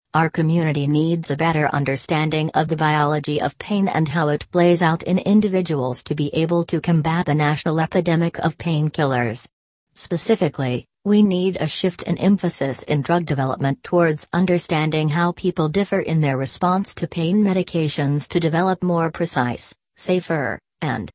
What happens is the audio does the last word or the phrase will be missing and the word or phrase will be replaced by a beep sound.
You will hear a lecture about painkillers.